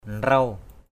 /nrau/ (d.) phở, nước lèo = soupe chinoise.